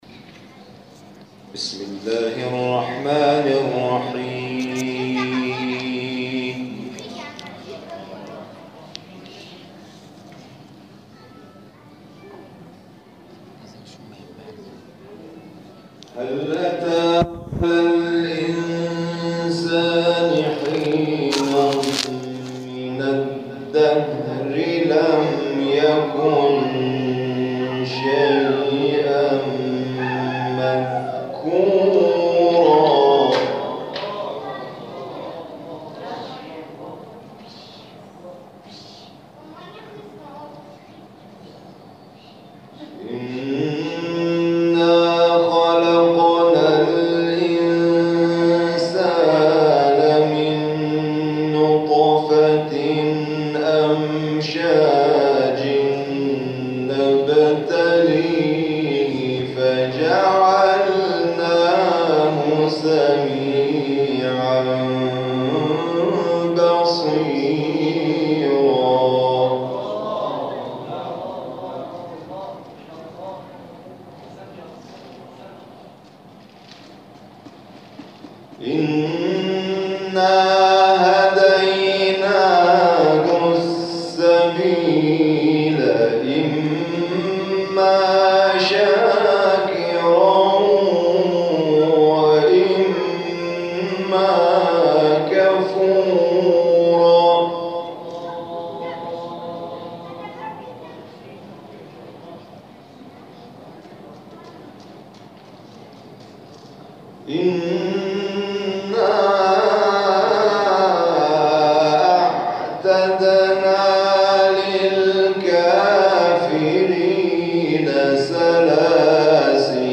گروه فعالیت‌های قرآنی: محفل انس با قرآن کریم، شب گذشته، یکم تیرماه در مسجد الجلیل تهران برگزار شد.